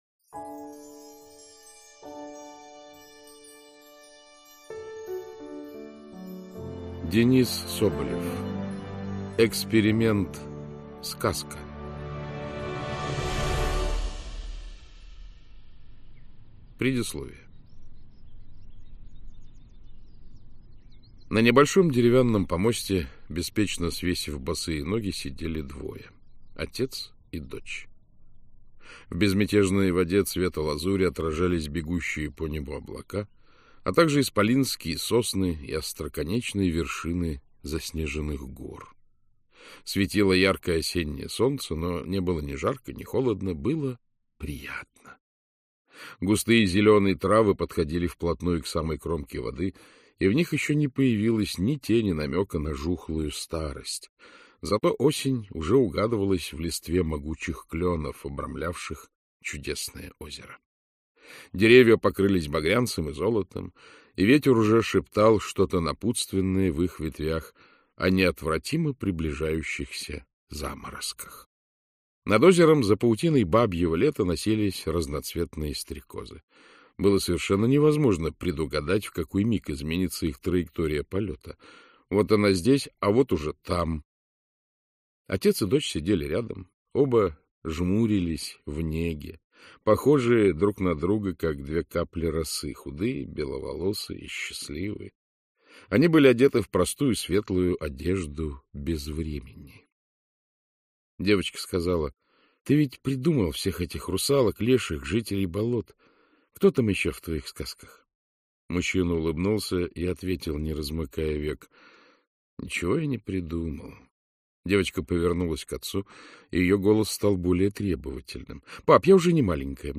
Аудиокнига Эксперимент СКАЗКА | Библиотека аудиокниг
Aудиокнига Эксперимент СКАЗКА Автор Денис Соболев Читает аудиокнигу Александр Клюквин.